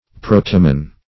Search Result for " protamin" : The Collaborative International Dictionary of English v.0.48: Protamin \Pro"ta*min\, n. [Gr. prw^tos first.]